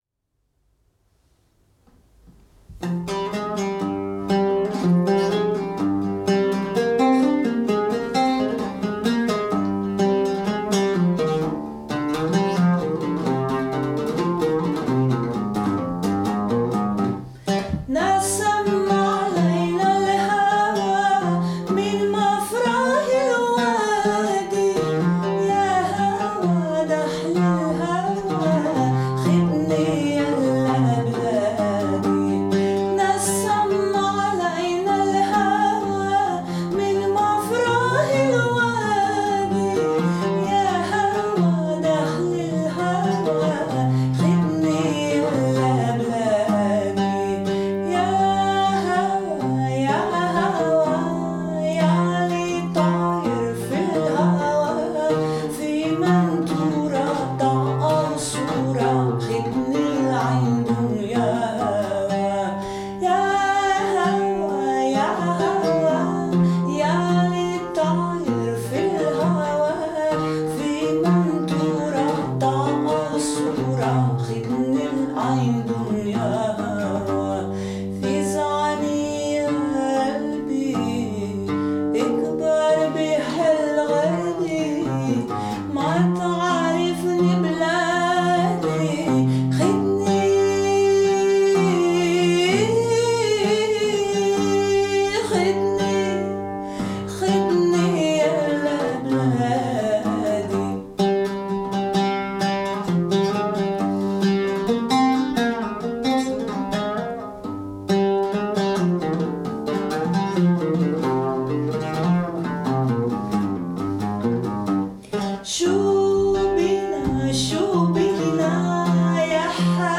(voice)